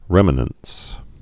(rĕmə-nəns)